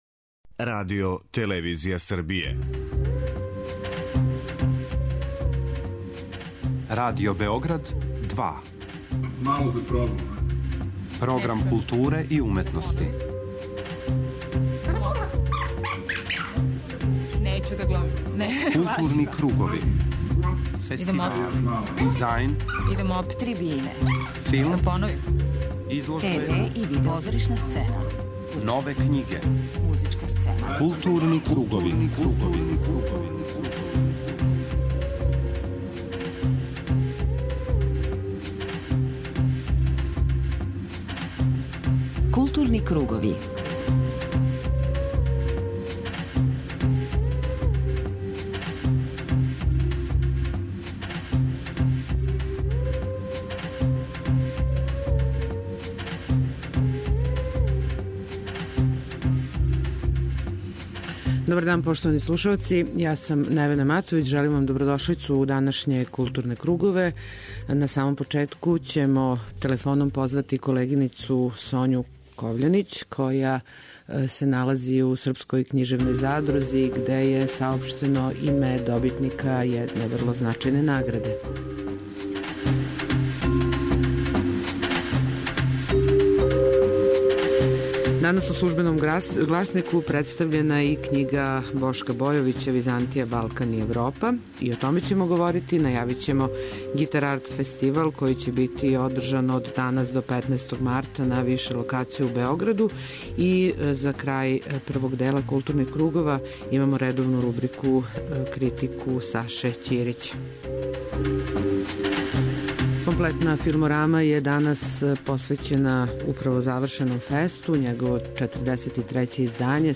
У Филморами ћете чути разговоре са награђеним ауторима, као и разговоре са гостима фестивала чији су филмови обележили овогодишње издање.